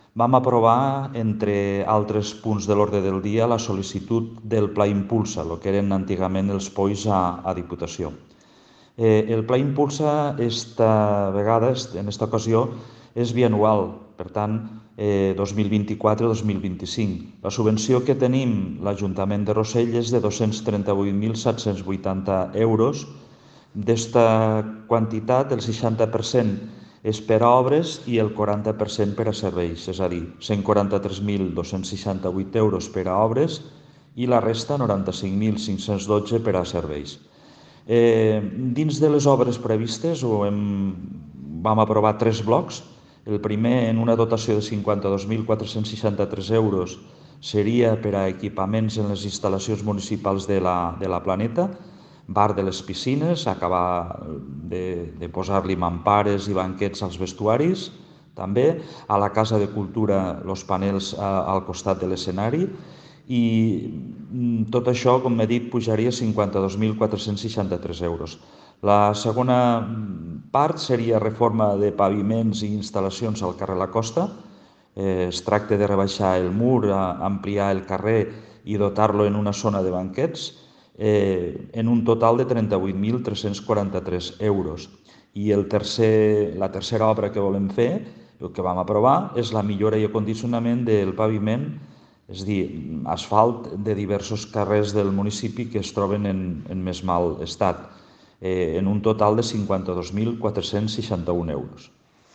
Evaristo Martí , alcalde de Rosell